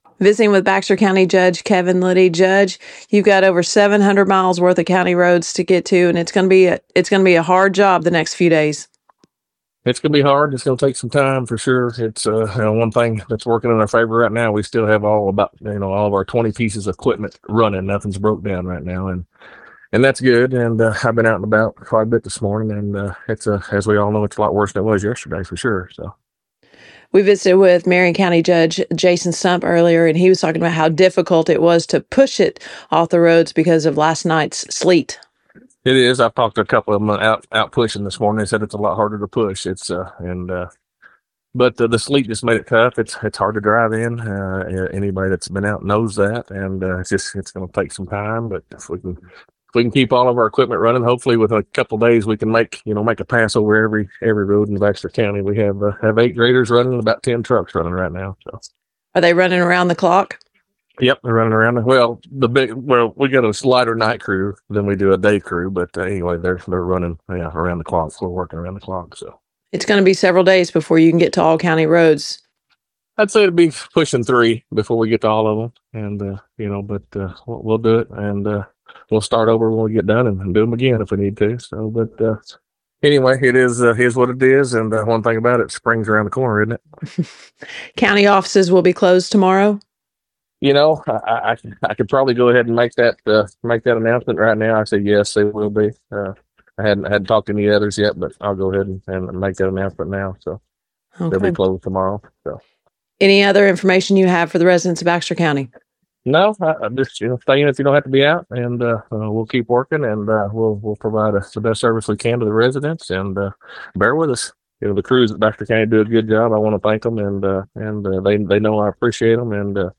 Categories: Arkansas NewsKTLO